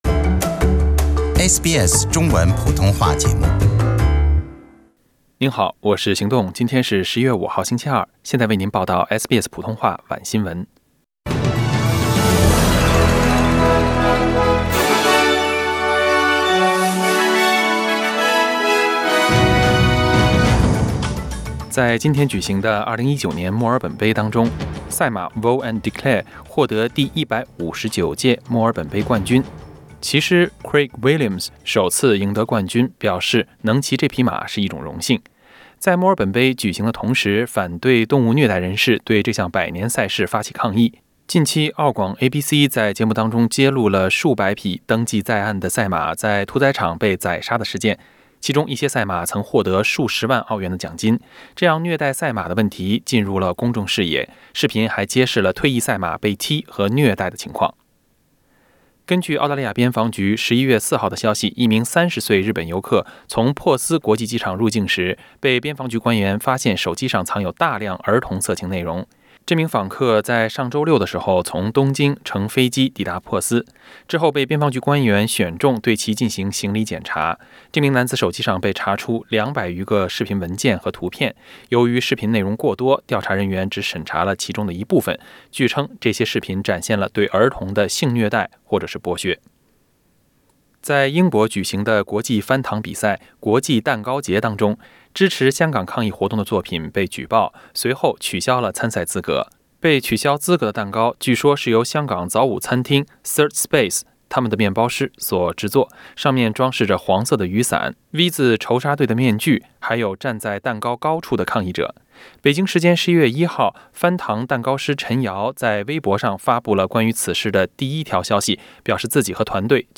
SBS晚新闻 （11月5日）